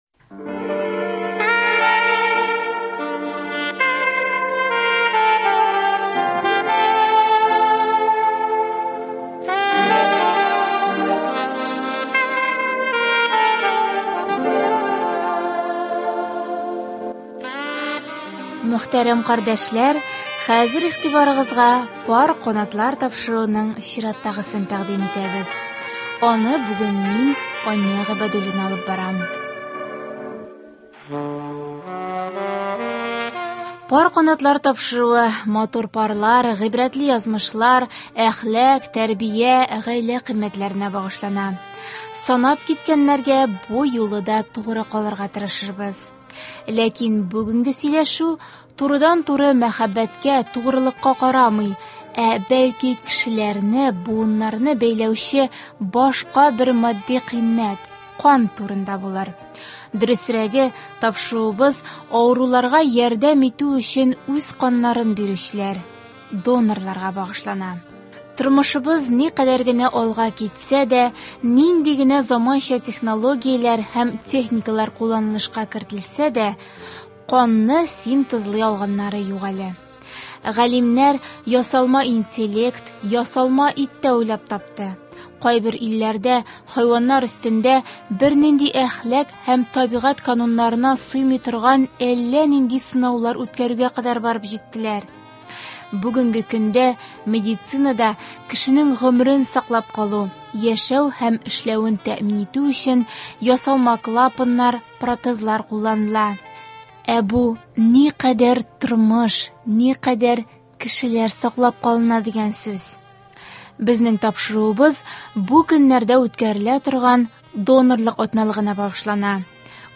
тапшыруда шулай ук күп тапкырлар кан тапшырган донорлар да катнаша.